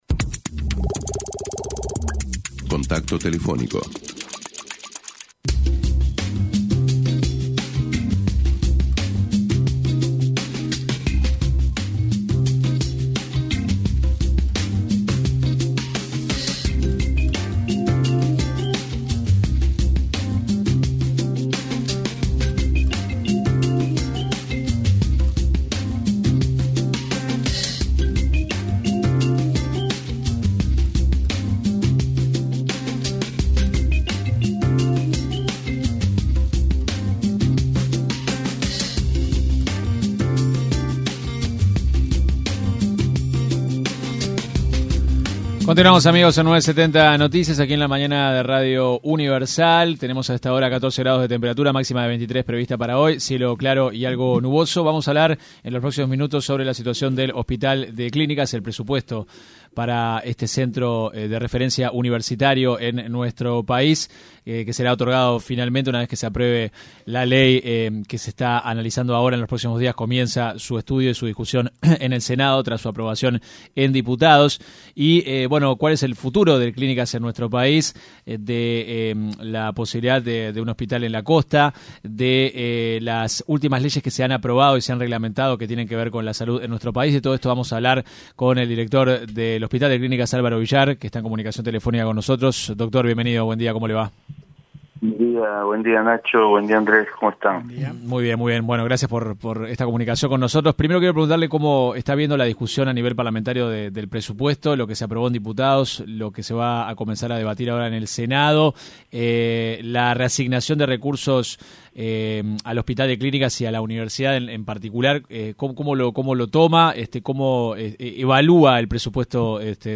se refirió en una entrevista con 970 Noticias, a la reglamentación de la ley de cuidados paliativos y valoró esta instancia.